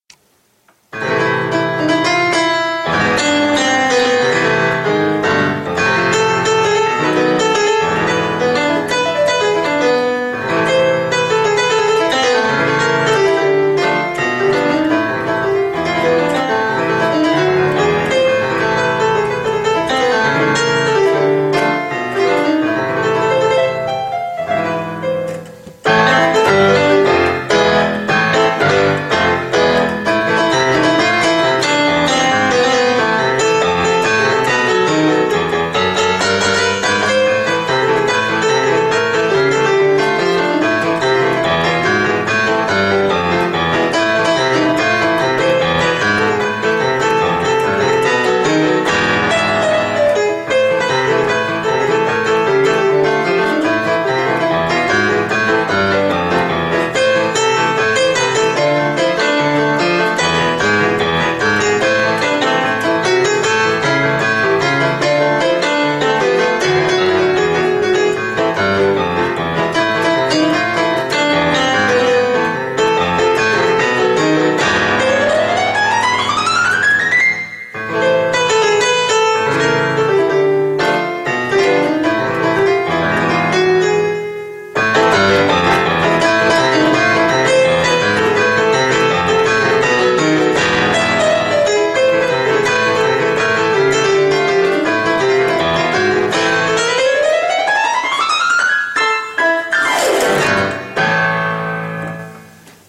Третий раз слушаю....так и хочется в этот шедевр местами паузы вставить :)
...Как-будто, чтобы побыстрее отвязаться от надоедливых гостей и убежать на улицу...:)